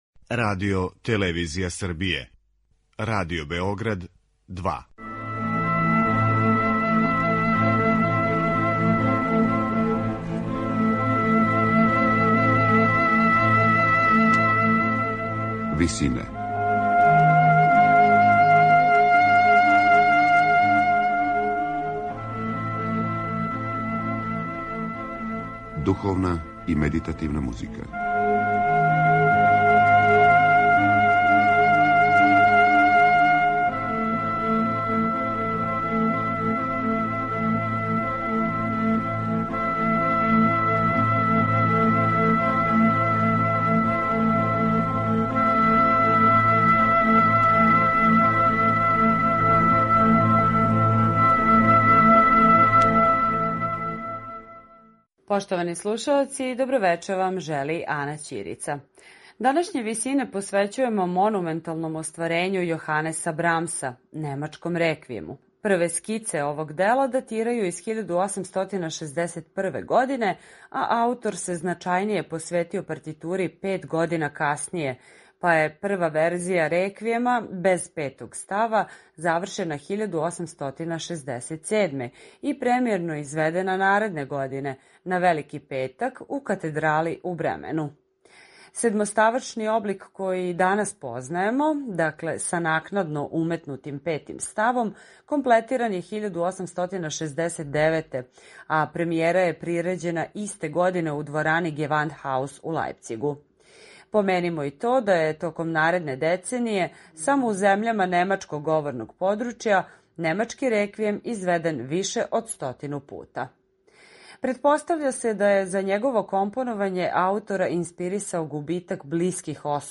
сопран
баритон